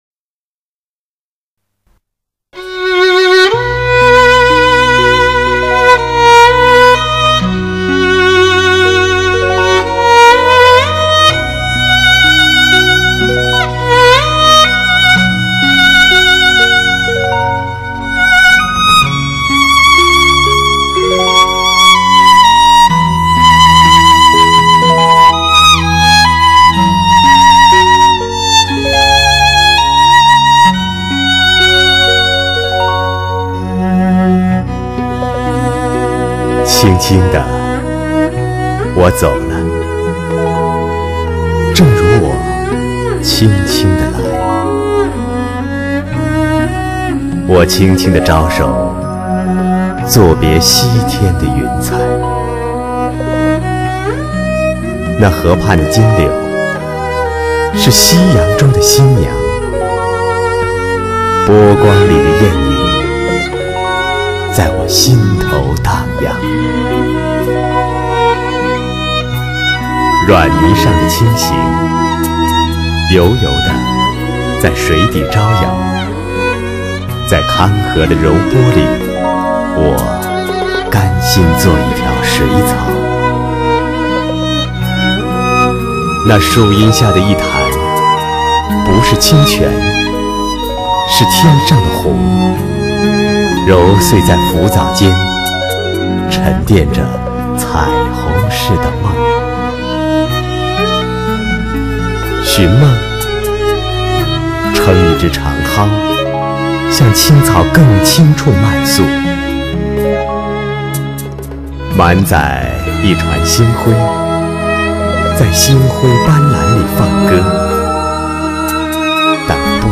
[8/12/2007]【配乐诗朗诵】徐志摩《再别康桥》美诗美乐 珠联璧合